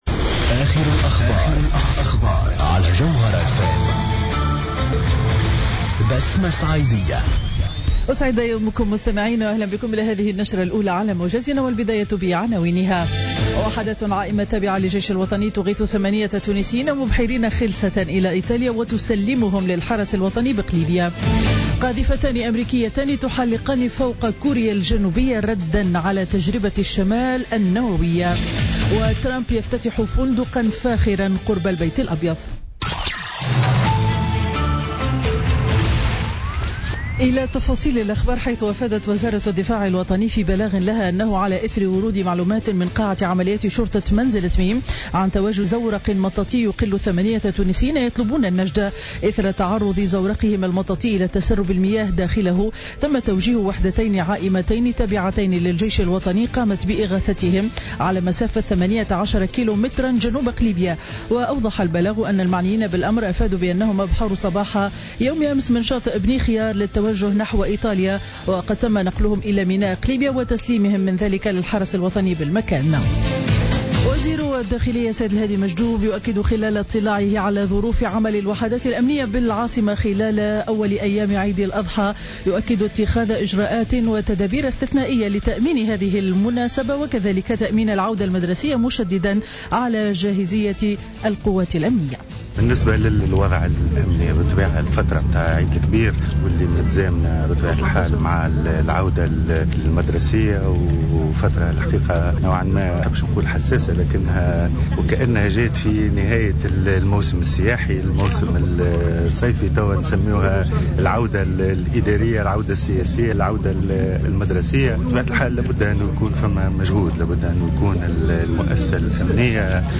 نشرة أخبار السابعة صباحا ليوم الثلاثاء 13 سبتمبر 2016